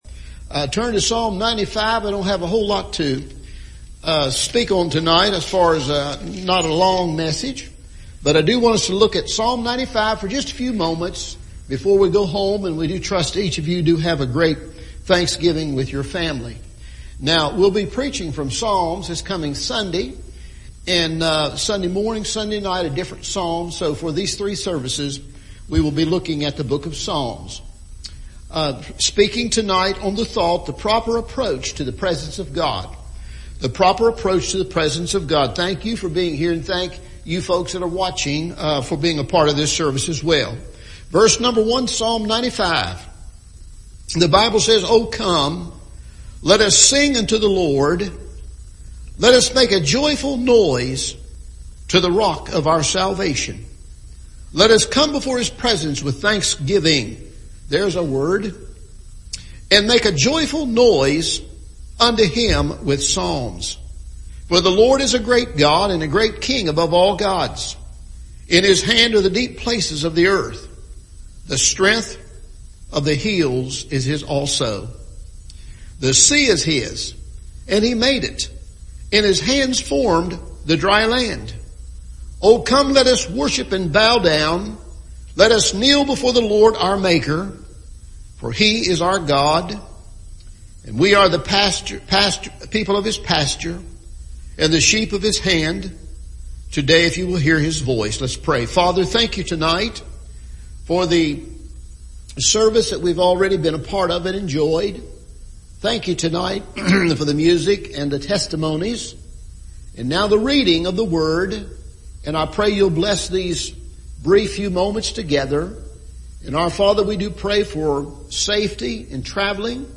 Psalm Study